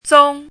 zōng
拼音： zōng
注音： ㄗㄨㄥ
zong1.mp3